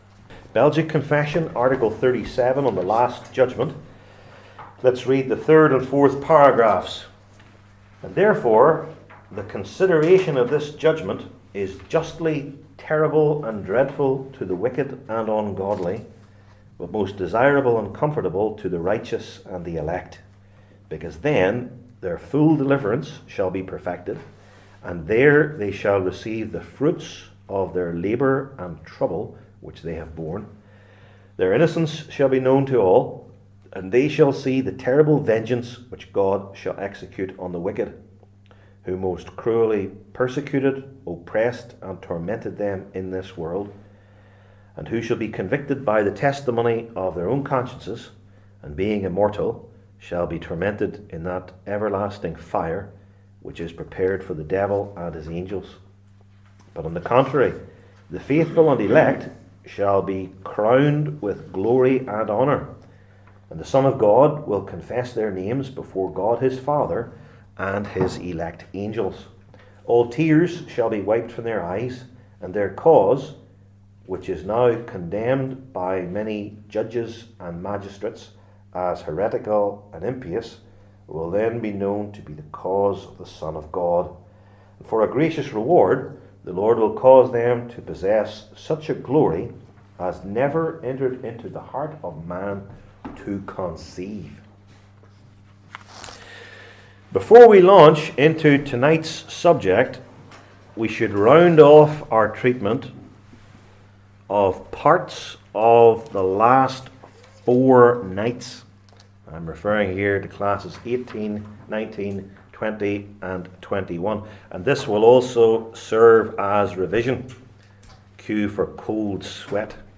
Revelation 17 Service Type: Belgic Confession Classes THE LAST JUDGMENT …